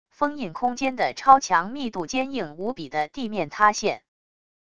封印空间的超强密度坚硬无比的地面塌陷wav音频